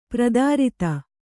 ♪ pradārita